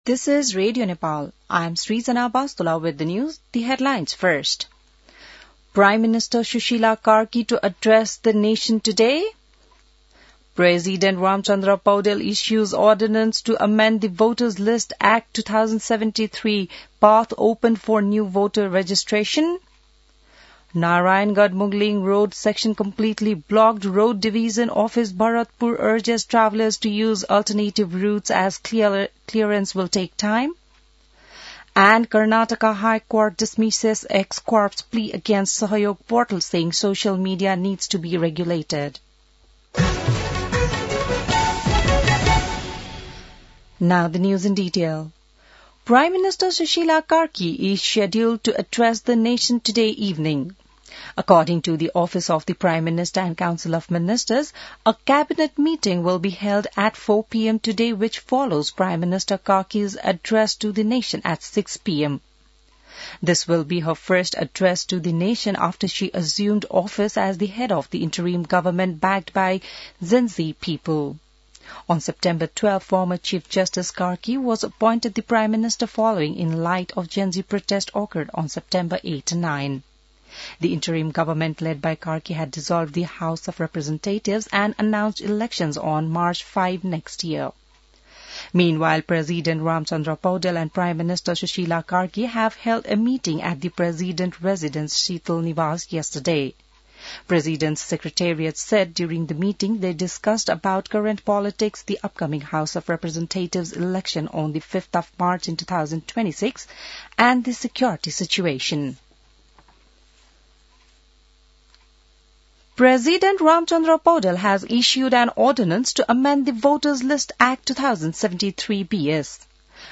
बिहान ८ बजेको अङ्ग्रेजी समाचार : ९ असोज , २०८२